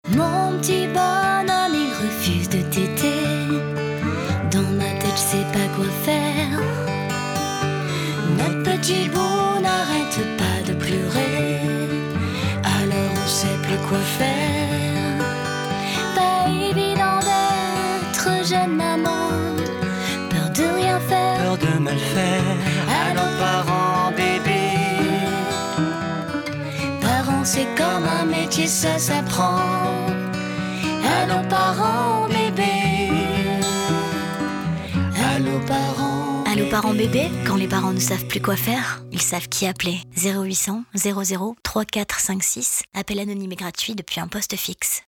Comédienne voix et chanteuse - voix adulte et enfant - Pubs TV Radios Voix Off Jingle Voix dessins animés chansons - français anglais allemand italien -
Sweet sung voice